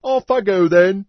PeasantYes4.mp3